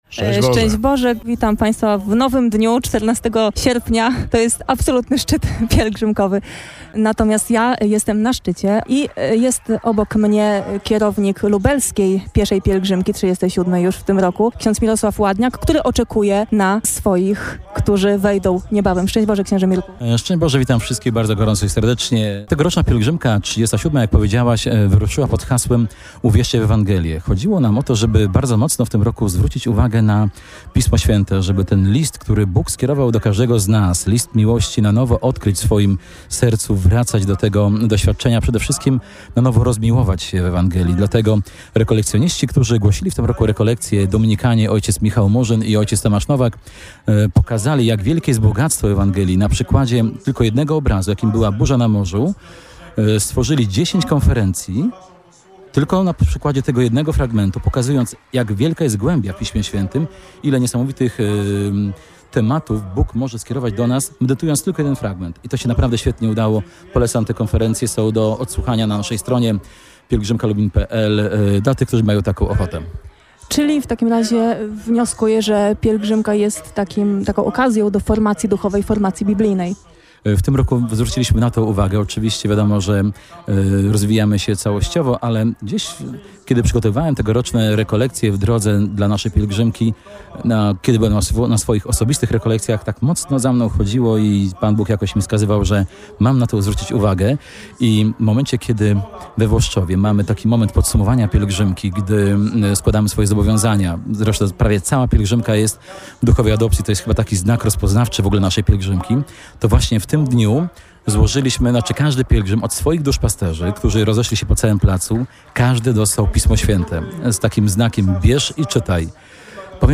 (Materiały audio wyemitowane na falach Radia Jasna Góra)